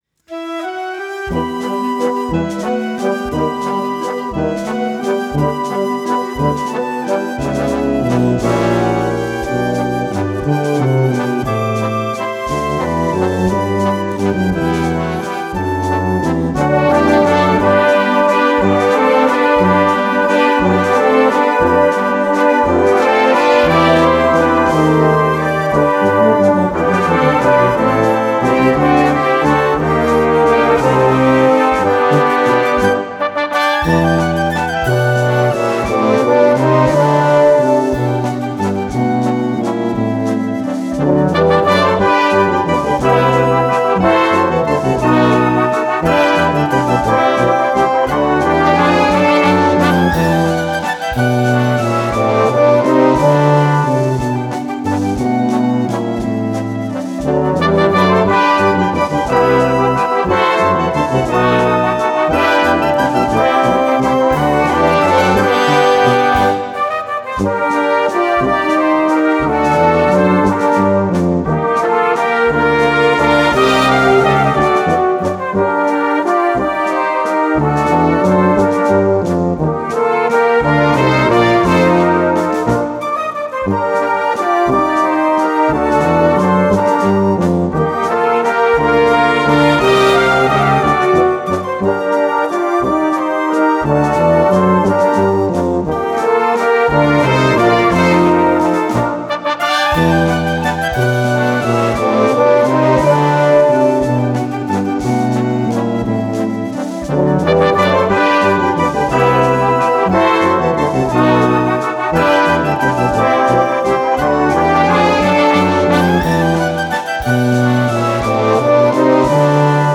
Walzer für Blasmusik